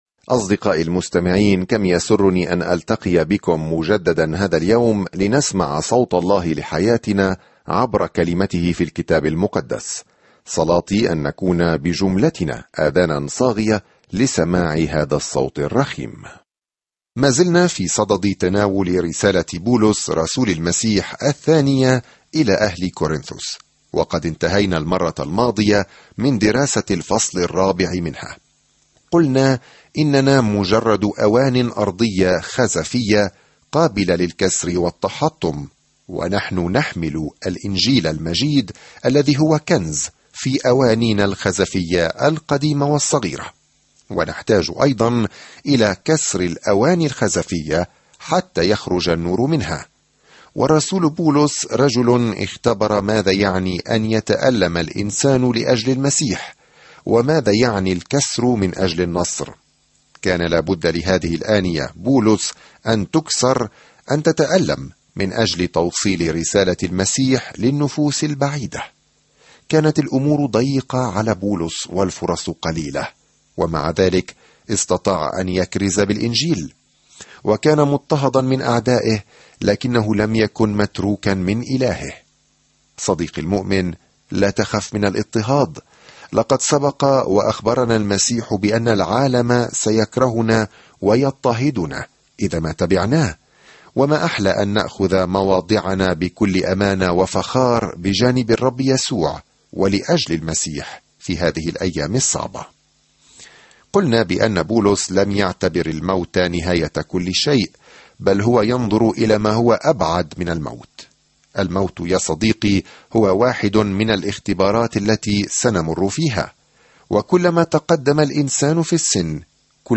There is an audio attachment for this devotional.